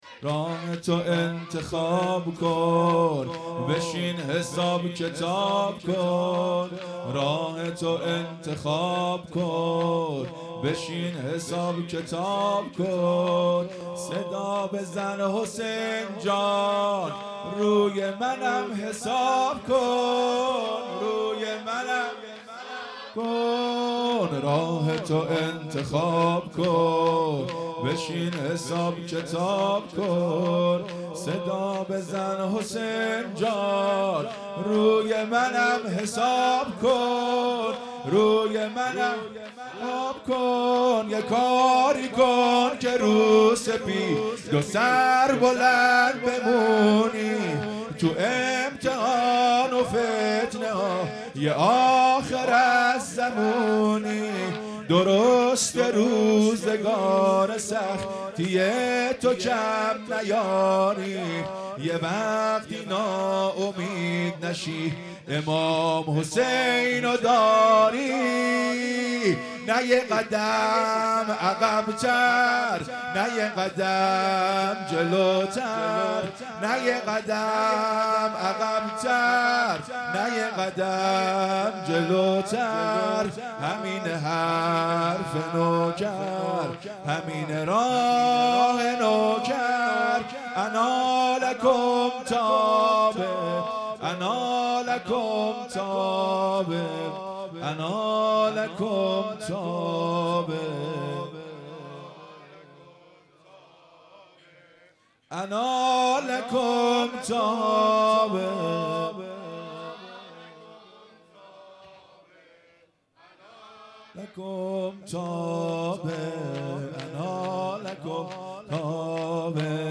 هیئت مکتب الزهرا(س)دارالعباده یزد
شور | راهت رو انتخاب کن بشین حساب کتاب کن
محرم ۱۴۴۵_شب چهارم